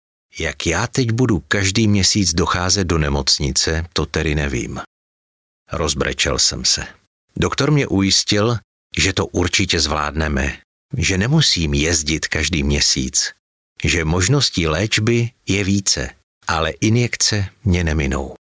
Profesionální český voiceover
• Nabízím profesionální, příjemný mužský hlas ošlehaný téměř 30 lety zkušeností u mikrofonů v rádiích i studiích.
Profesionální, zralý mužský hlas pro váš voiceover, reklamu, hlasovou aplikaci nebo dabing